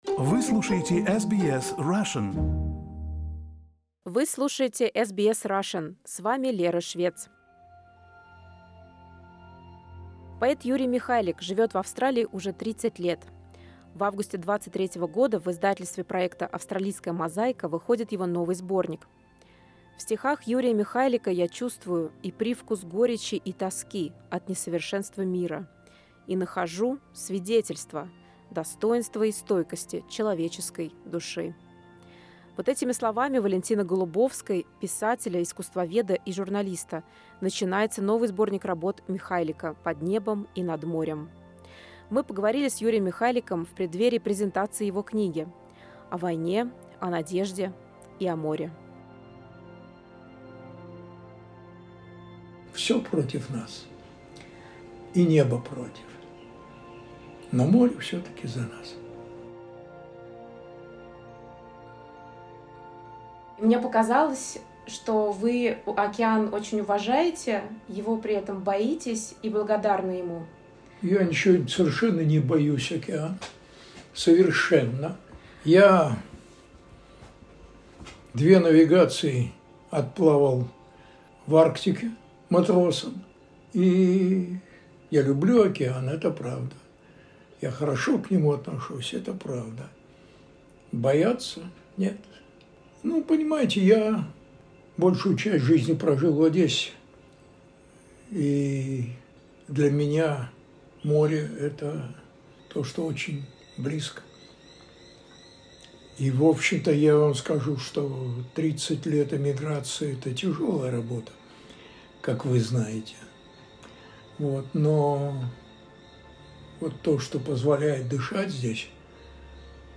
В подкасте прозвучали стихи